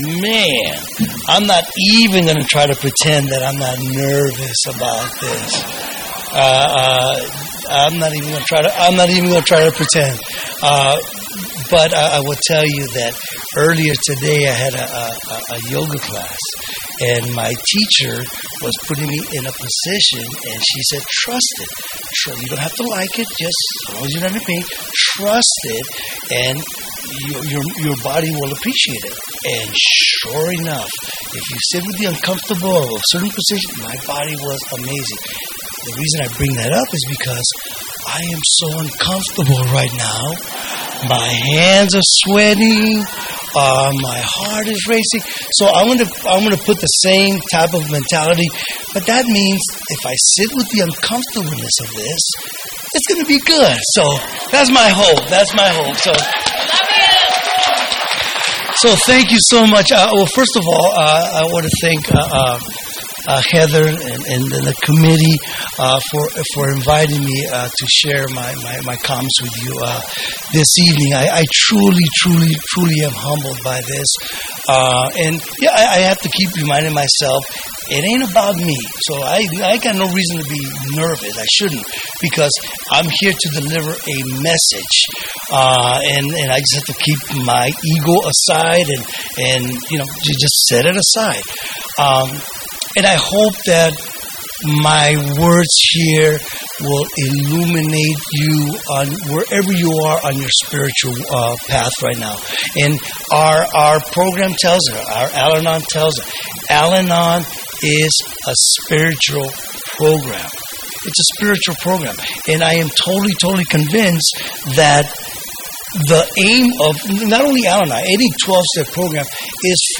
San Fernando Valley AA Convention 2024 - Let Go and Let God